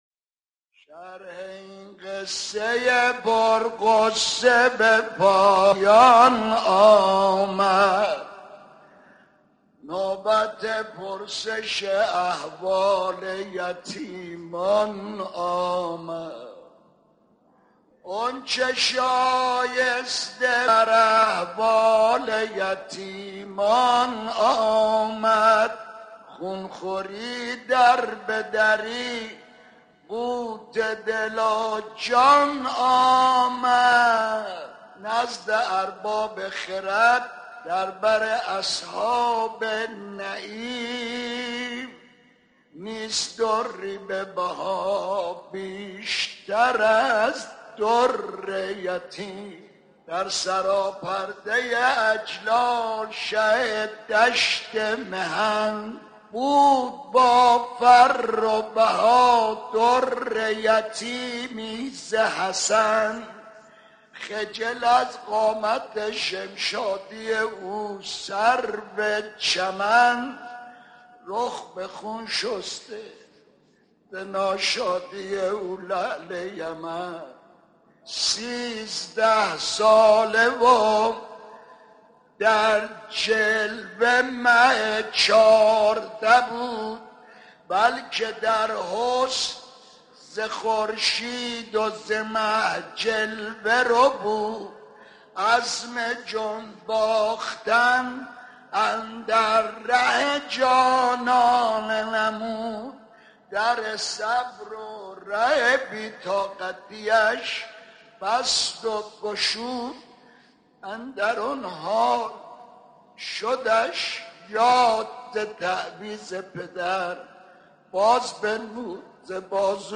مدح - غصه به پایان رسید